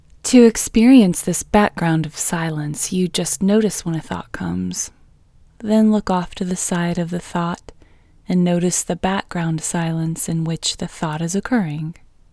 QUIETNESS Female English 6